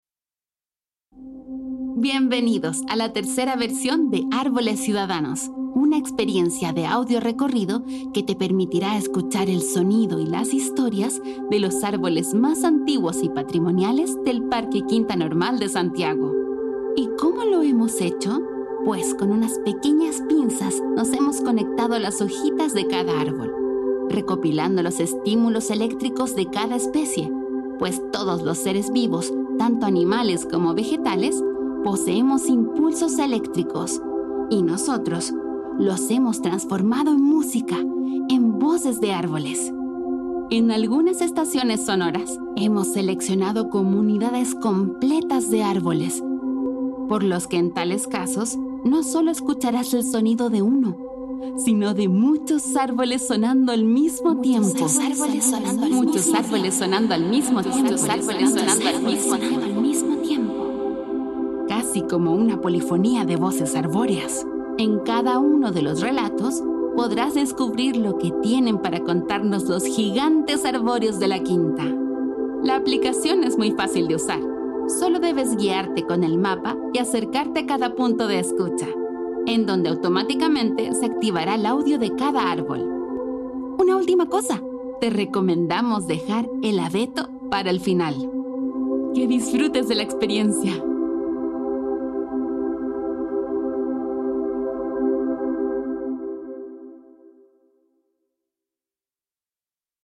Bienvenidos y bienvenidas a Árboles Ciudadanos III, una experiencia de audio recorrido que te permitirá escuchar el sonido y las historias de las comunidades de árboles más longevas y patrimoniales del Parque Quinta normal de Santiago.